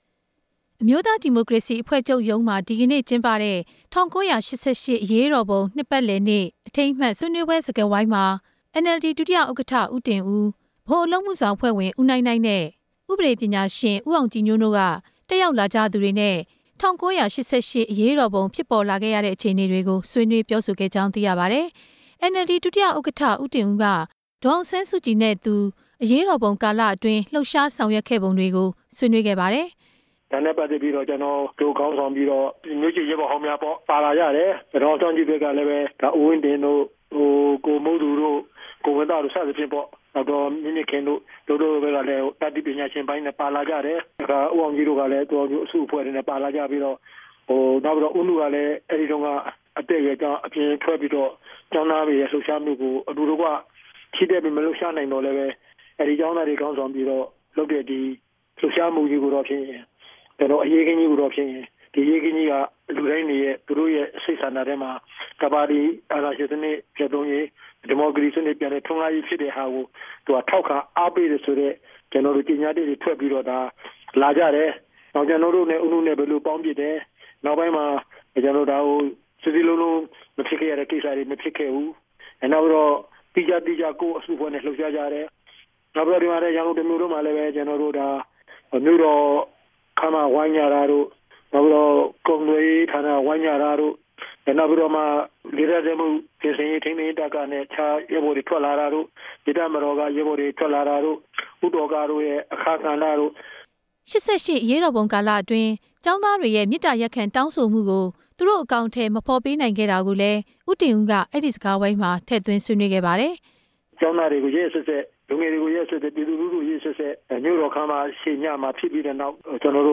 ၈၈ နိုင်ငံရေးအကျိုးဆက်အကြောင်း ဦးတင်ဦး ဟောပြော